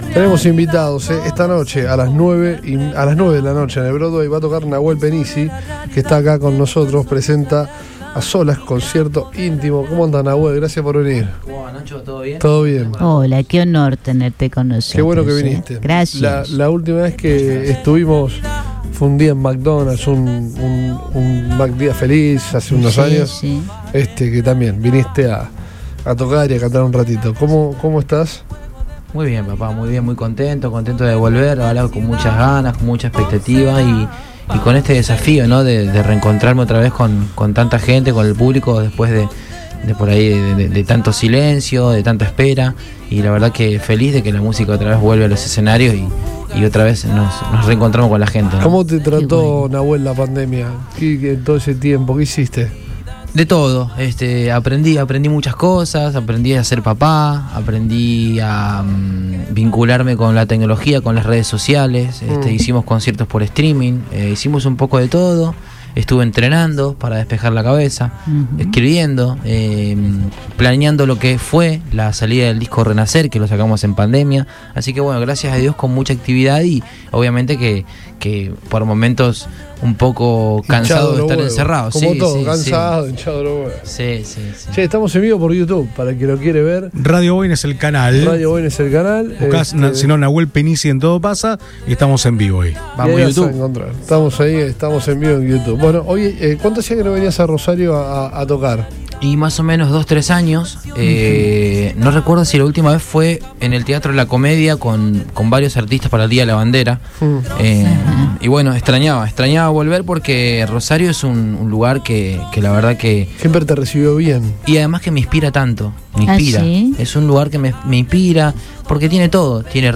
Nahuel Pennisi desembarcó en Rosario para presentar “A Solas”, concierto íntimo que se llevará a cabo este viernes en el Teatro Broadway. En la antesala a la función, el cantante visitó los estudios de Radio Boing y se refirió a sus orígenes en la música.